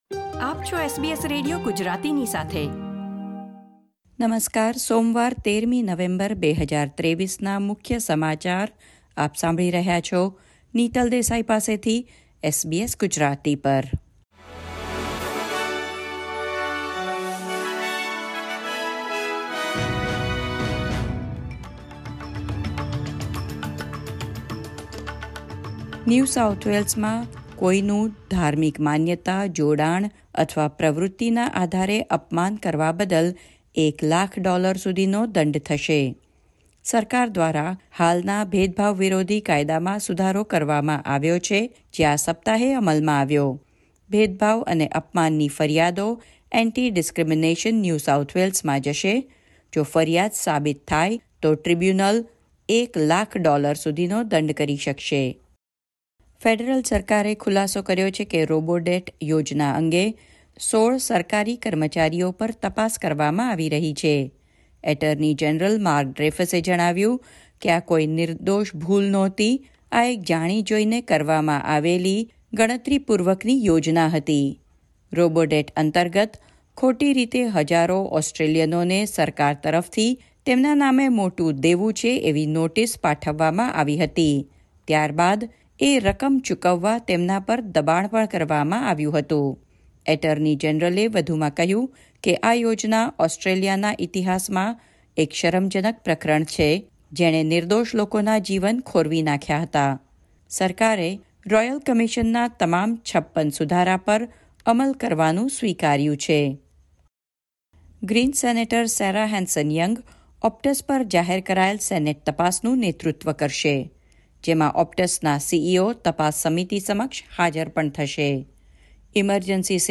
SBS Gujarati News Bulletin 13 November 2023